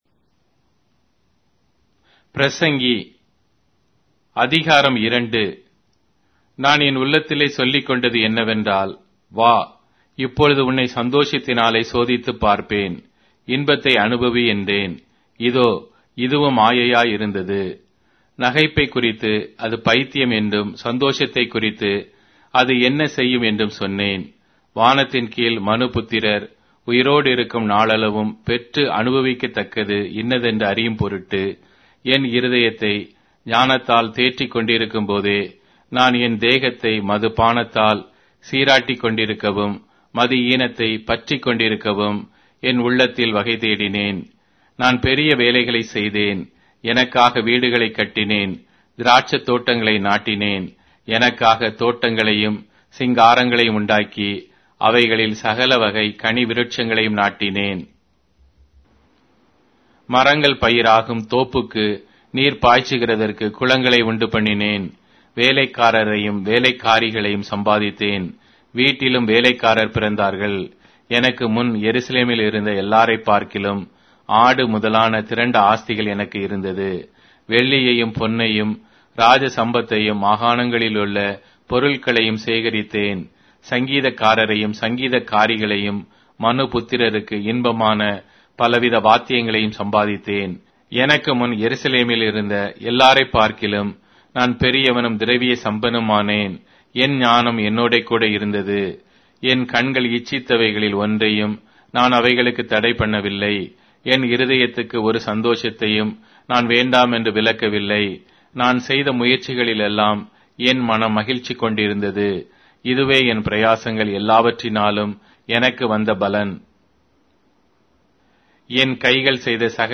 Tamil Audio Bible - Ecclesiastes 11 in Gnttrp bible version